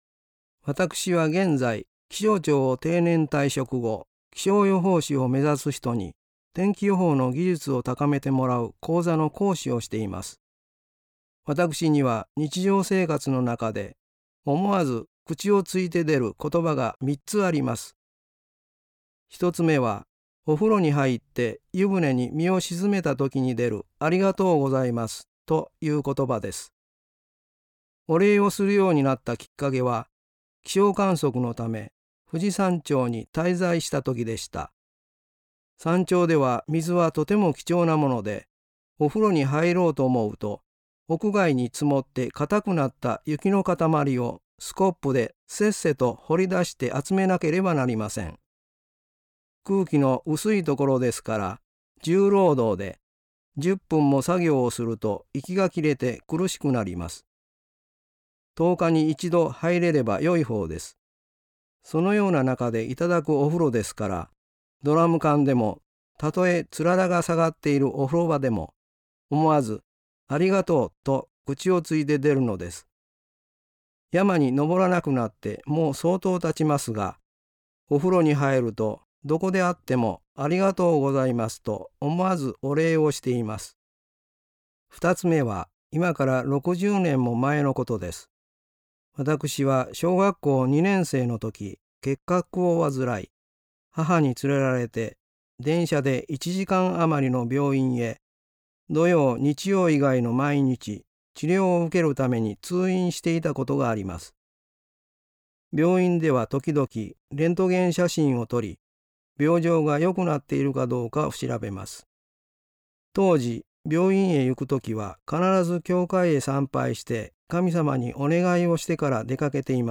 信者さんのおはなし